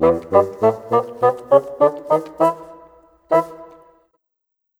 Rock-Pop 20 Bassoon 01.wav